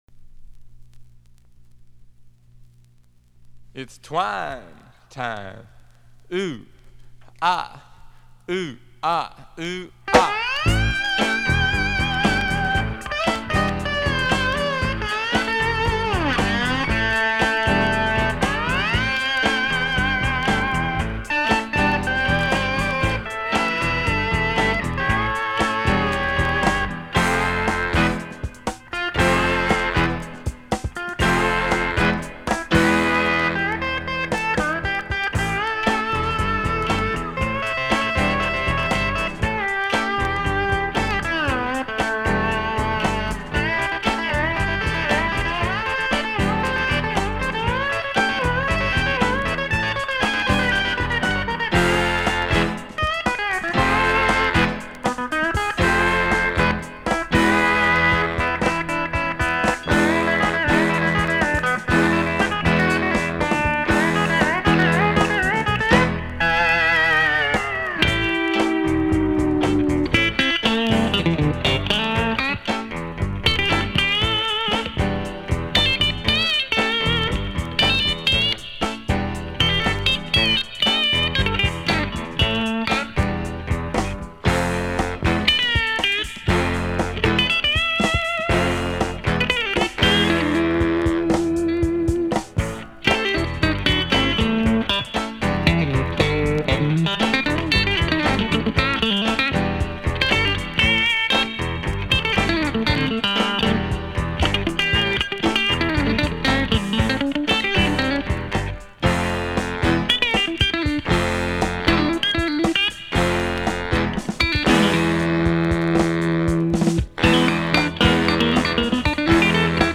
Cool souled out steel guitar instrumentals